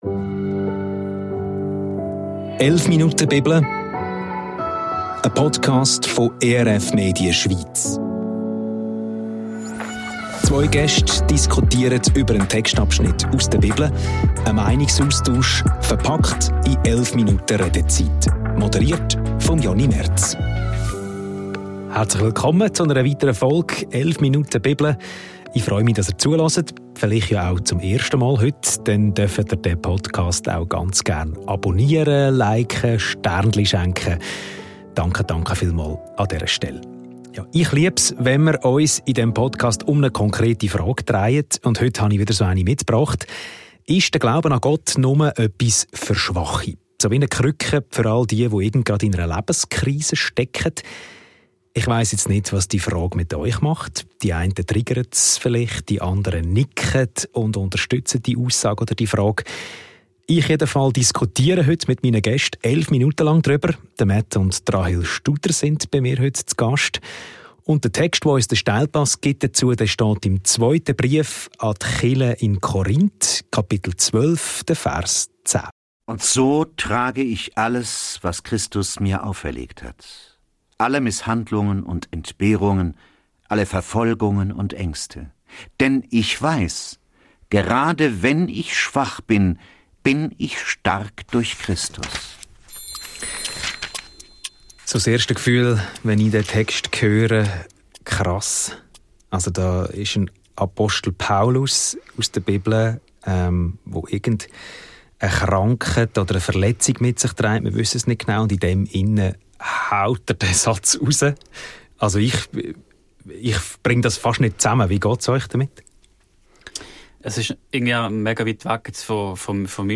Ist der Glaube an Gott nur etwas für Schwache? – 2. Korinther 12,10 ~ 11 Minuten Bibel – ein Meinungsaustausch Podcast